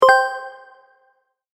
ボタン・システム （87件）
決定16.mp3